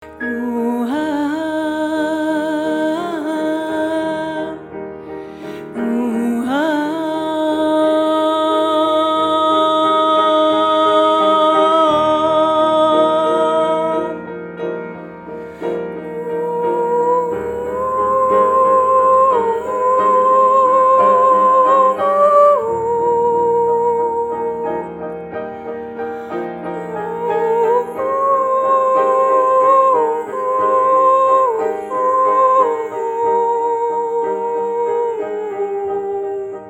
Poesie
Momentum-Aufnahmen